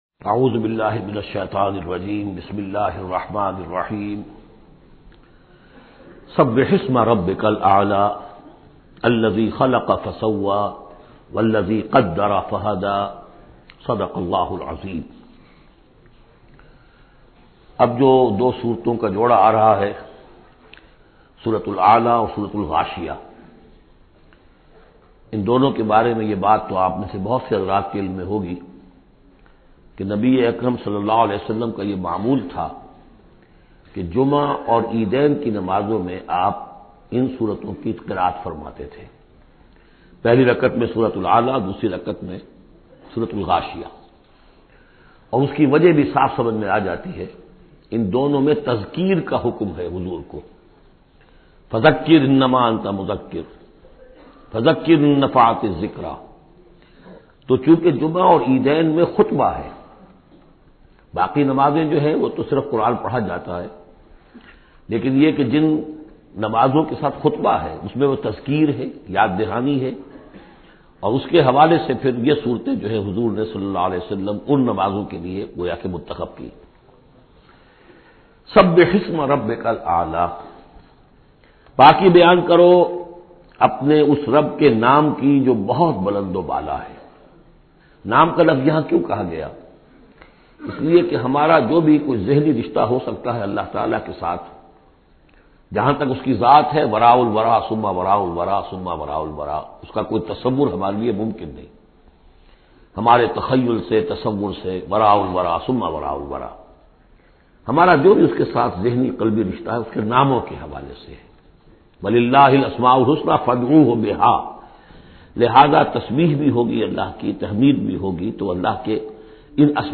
Surah Al-Ala, listen online mp3 audio tafseer in the voice of Dr Israr Ahmed.